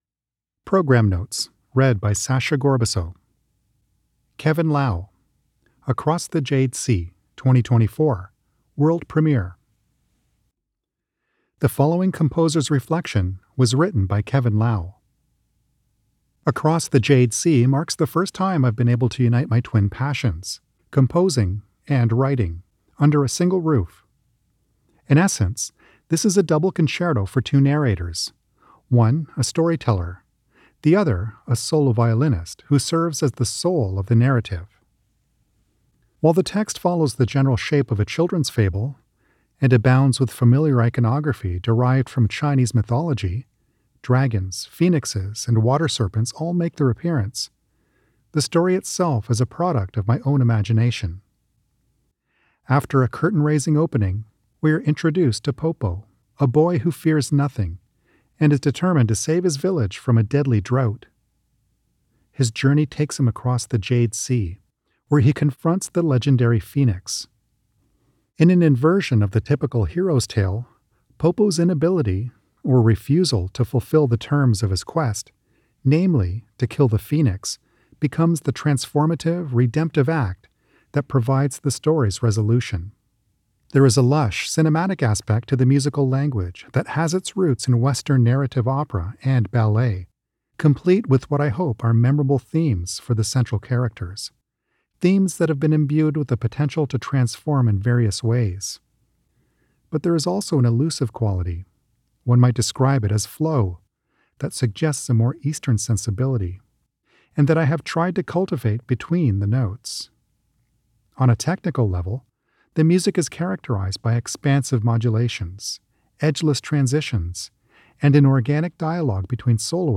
Audio Program Notes